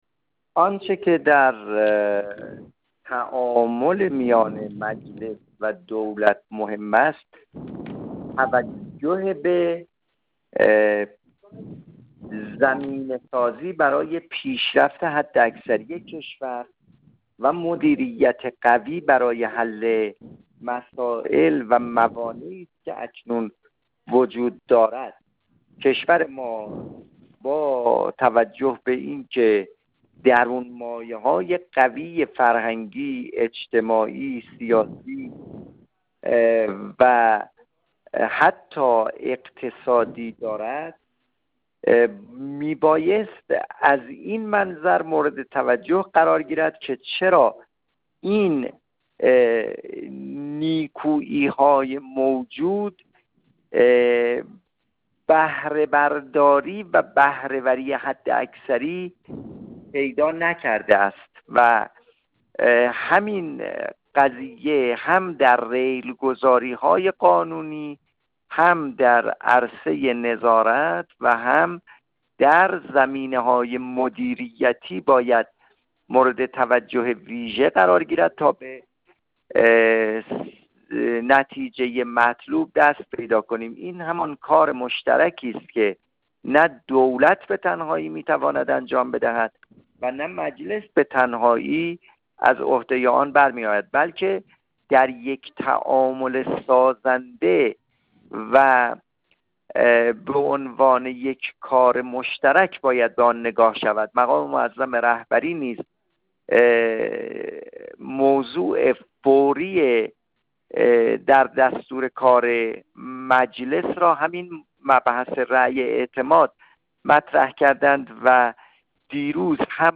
عباس مقتدایی، نایب رئیس اول کمیسیون امنیت ملی و سیاست خارجی، در گفت‌وگو با ایکنا درباره توصیه‌های مقام معظم رهبری در دیدار رئیس و نمایندگان مجلس و شکل‌گیری تعامل سازنده مجلس و دولت، گفت: در تعامل مجلس و دولت توجه به زمینه‌سازی برای پیشرفت حداکثری کشور و مدیریت قوی برای حل مسائل و موانع کنونی مهم است.